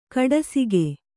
♪ kaḍasige